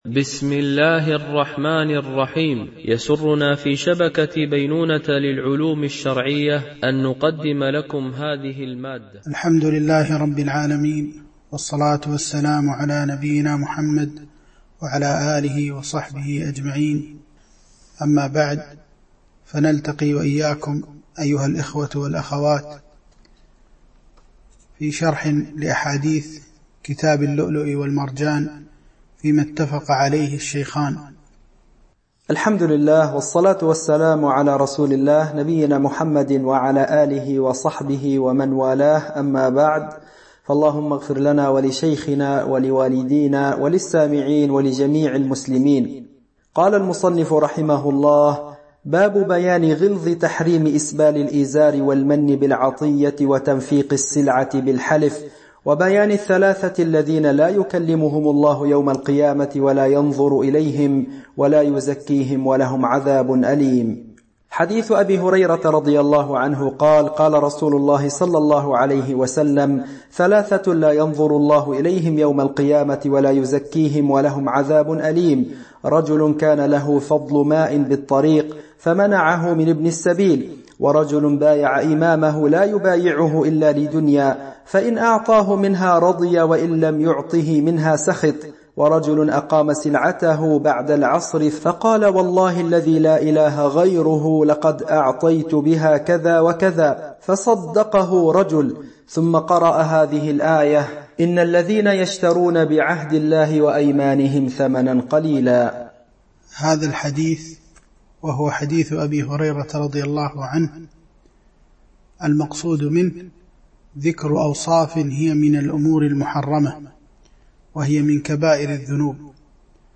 الدرس 8